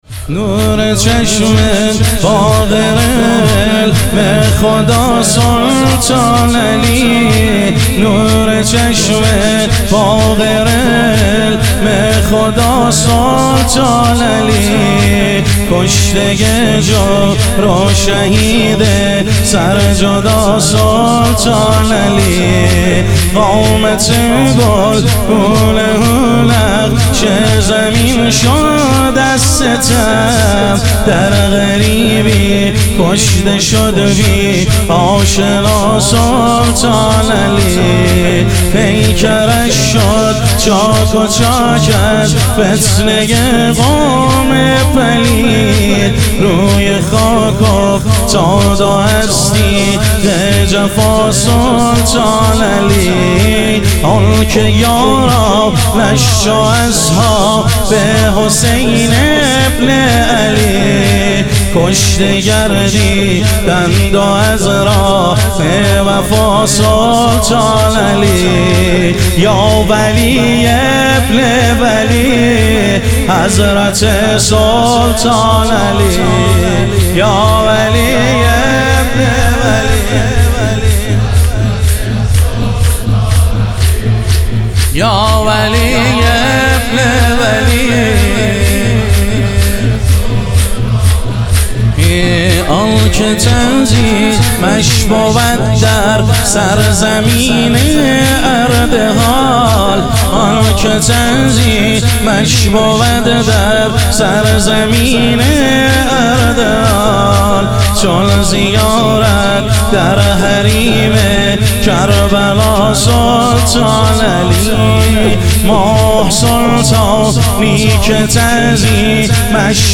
شور
شب شهادت حضرت سلطانعلی علیه السلام